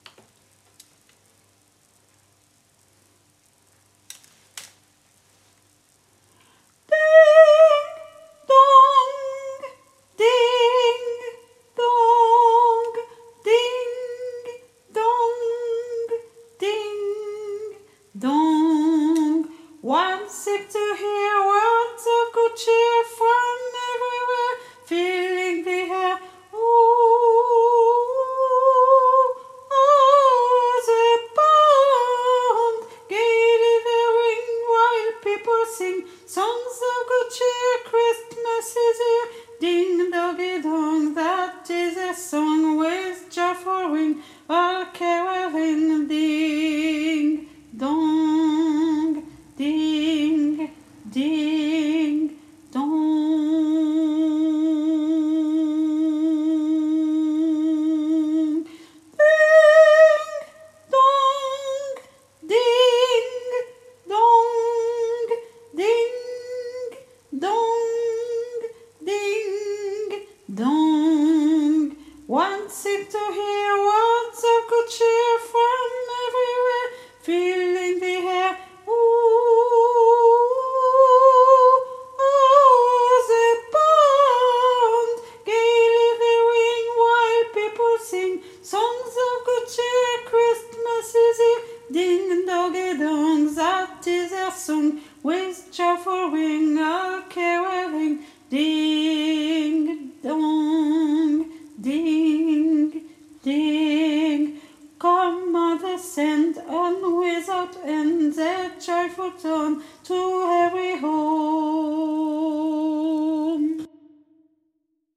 MP3 versions chantées
Tenor